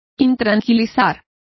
Complete with pronunciation of the translation of disquiet.